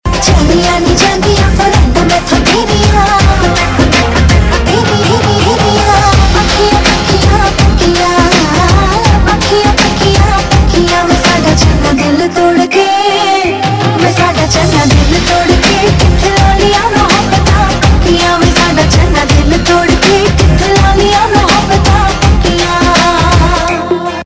Punjabi Mp3 Tone